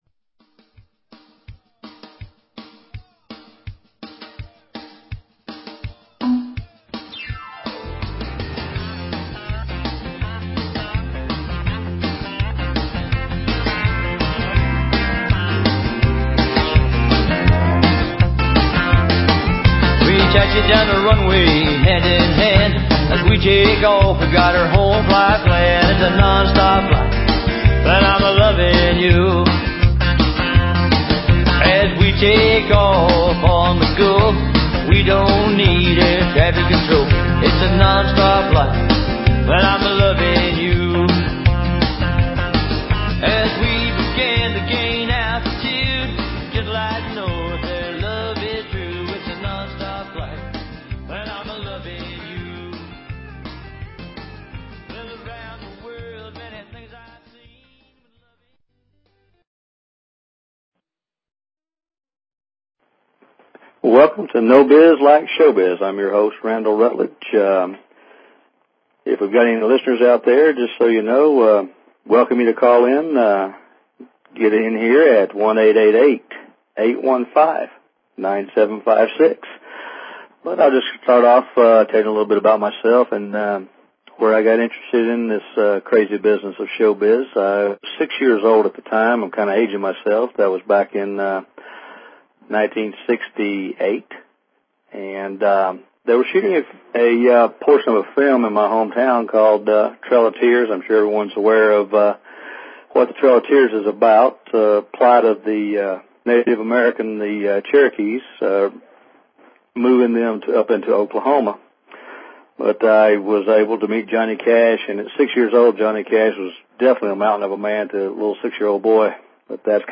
Talk Show Episode, Audio Podcast, No_Biz_Like_Showbiz and Courtesy of BBS Radio on , show guests , about , categorized as